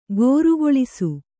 ♪ gōrugoḷisu